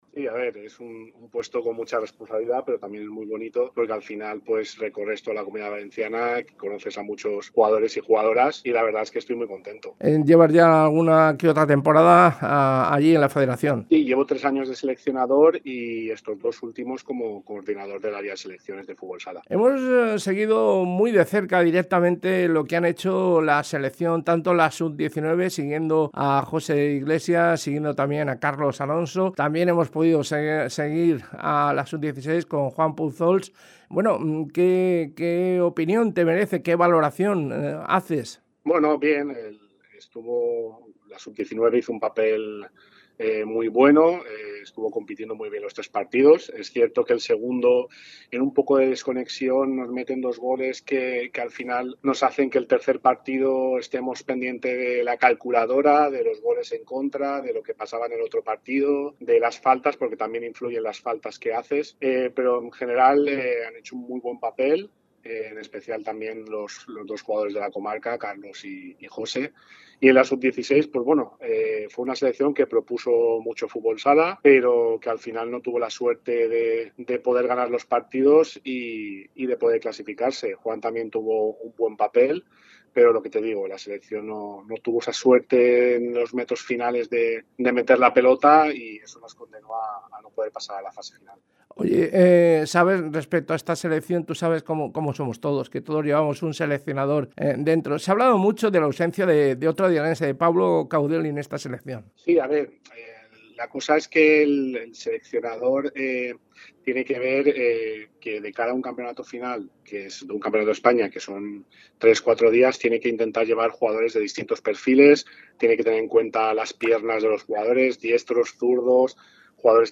fue entrevistado en DéniaFM